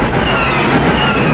Blaster Sound
blaster6.wav